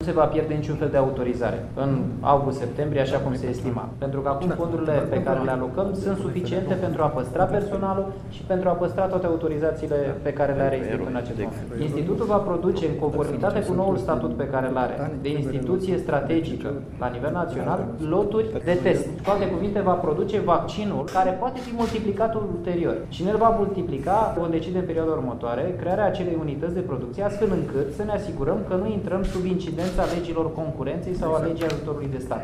Preşedintele comitetului interministerial care s-a ocupat de salvarea institutului – Daniel Constantin :